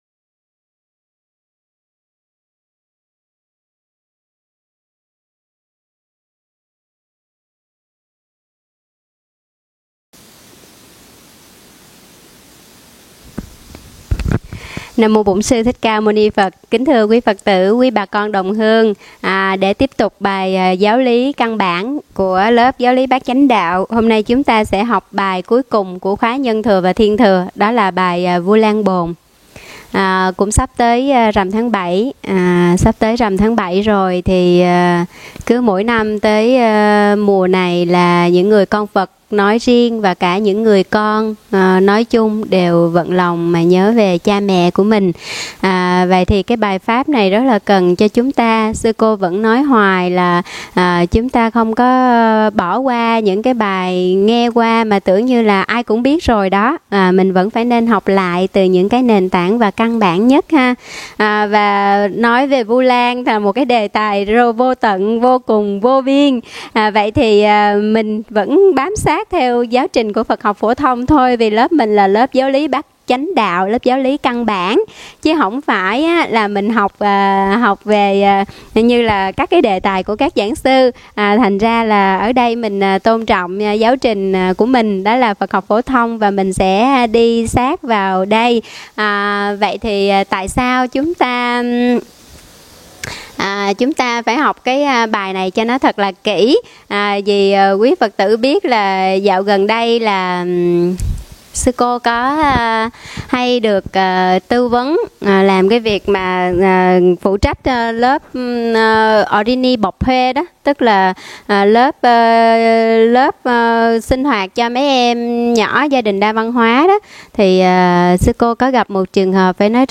Mời quý phật tử nghe mp3 thuyết pháp Vu Lan Bồn